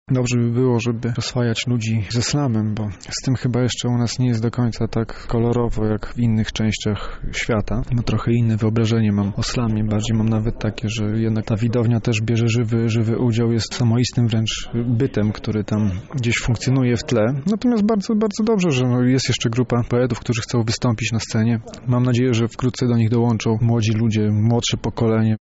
W sobotę w „Szklarni” przed mikrofonem stanęło kilkunastu slamerów i opowiadaczy.